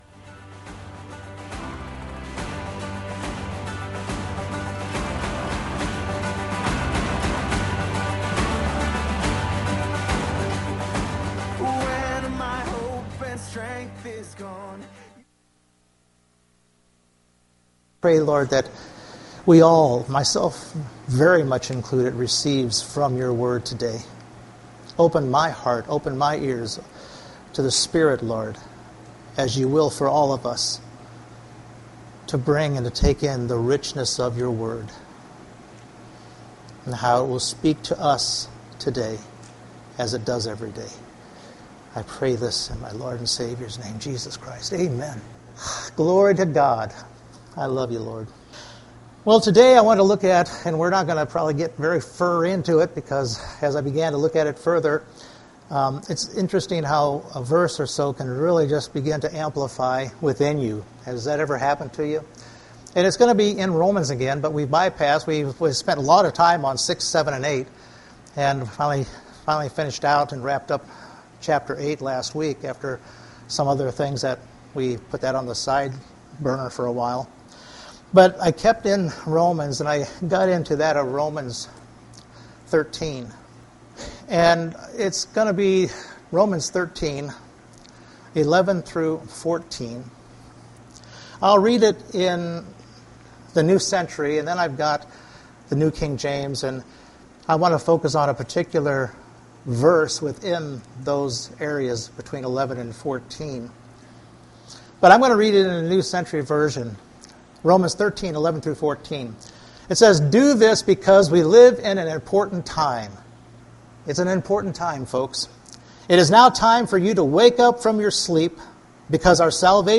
Romans 13:12 Service Type: Sunday Morning Romans 13:11 says to awake from your slumber our salvation is nearer.